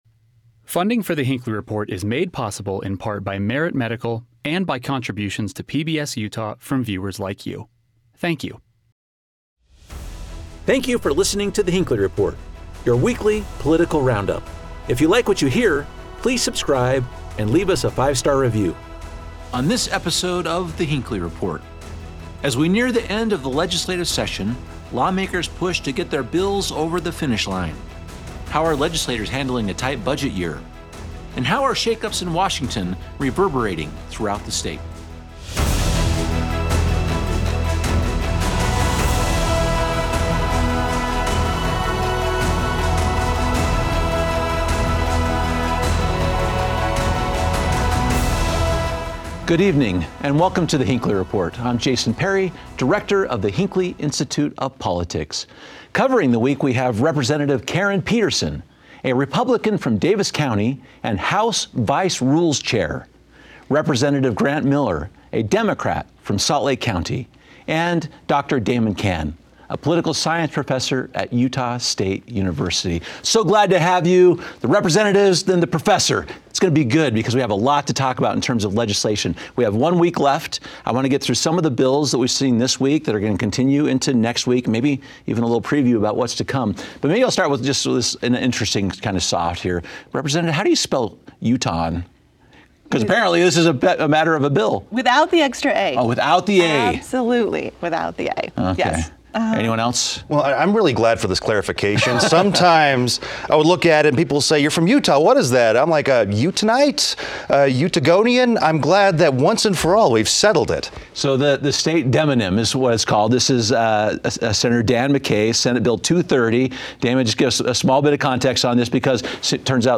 Our expert panel discusses how this proposal actually puts back in place an old system.